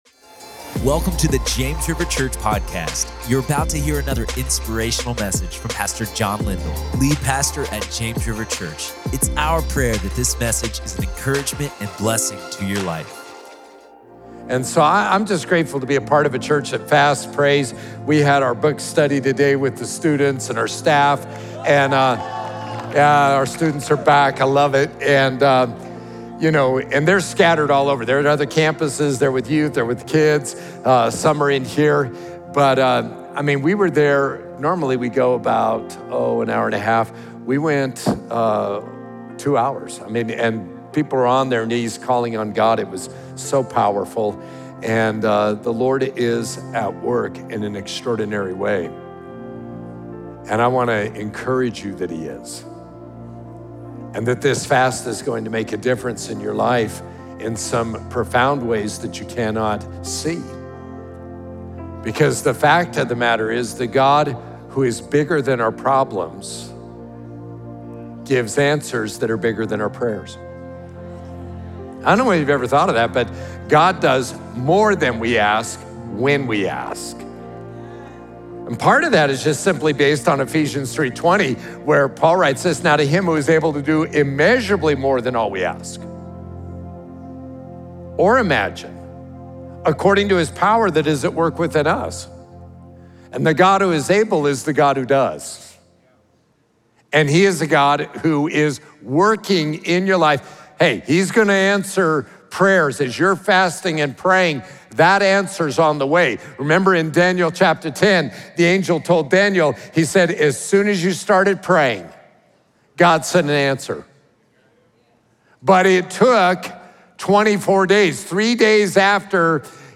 God Is Working While We Fast | Prayer Meeting